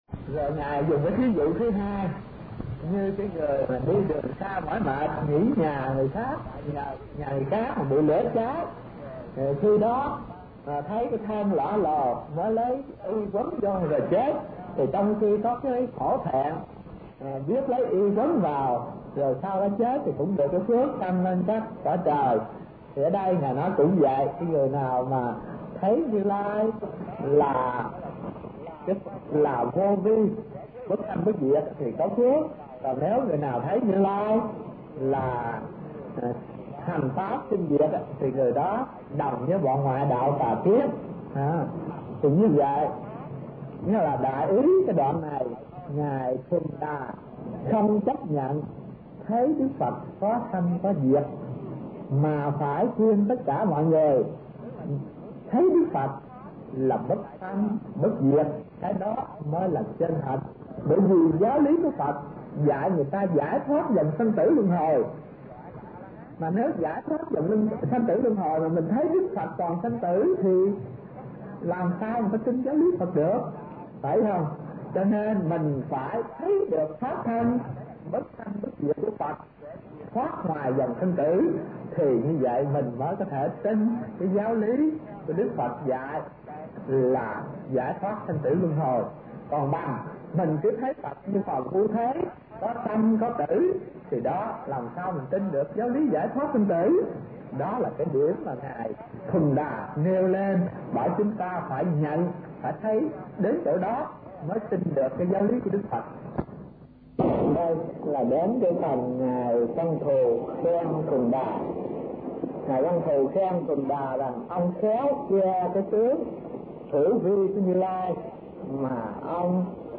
Kinh Giảng Đại Bát Niết Bàn